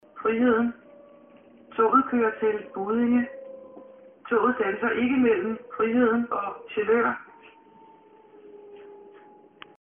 Højttalerudkald - "Næste station.."